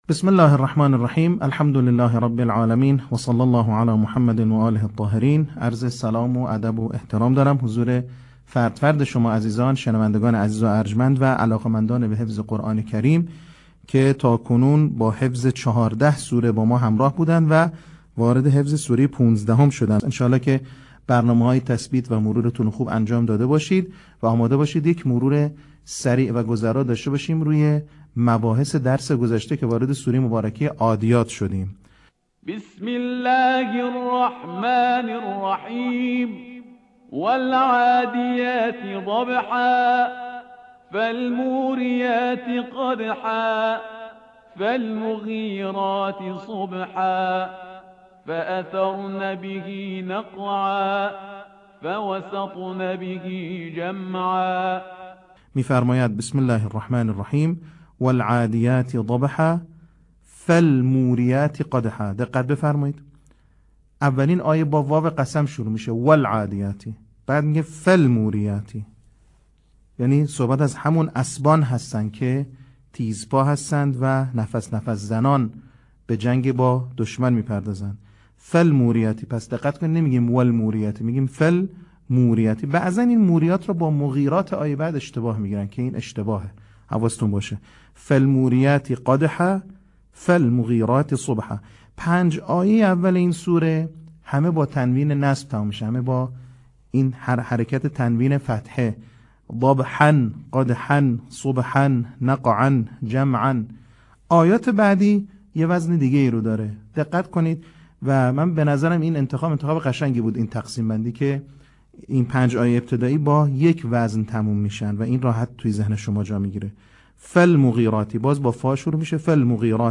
صوت | بخش دوم آموزش حفظ سوره عادیات
به همین منظور مجموعه آموزشی شنیداری (صوتی) قرآنی را گردآوری و برای علاقه‌مندان بازنشر می‌کند.